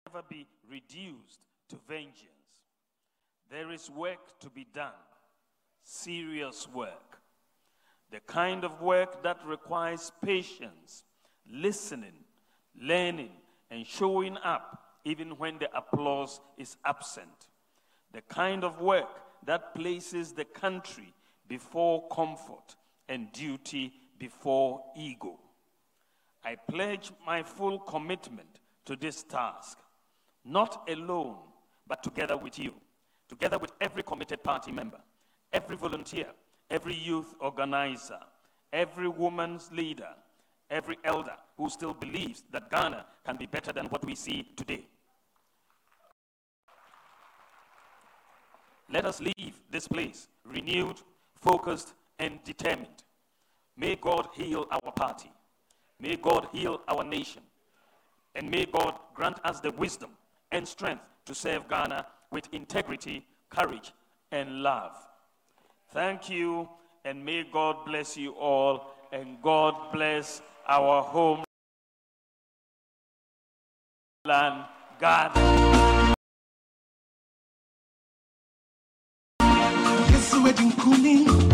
Speaking at the NPP’s Annual Thanksgiving Service on Sunday, Dr Bawumia said the country has endured hardship and remains under intense social and economic pressure, making it imperative for political actors to de-escalate tensions rather than inflame them.